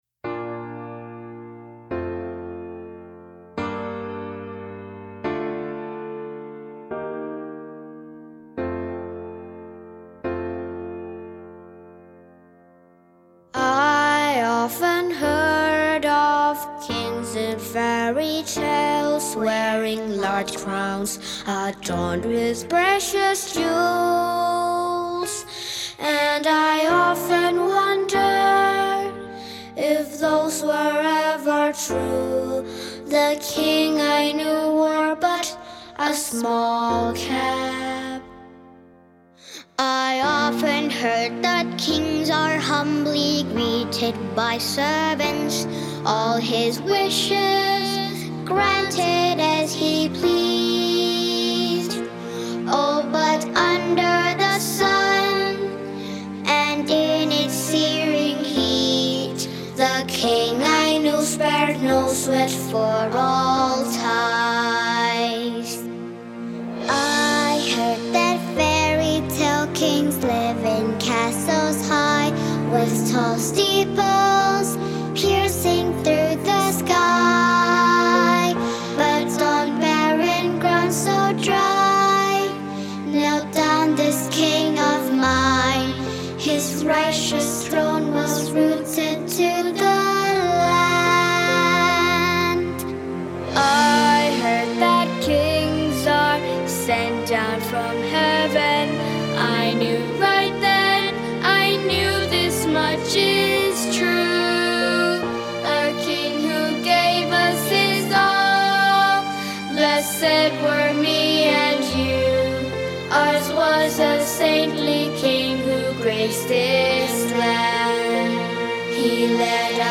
Soprano saxophone
Vocalists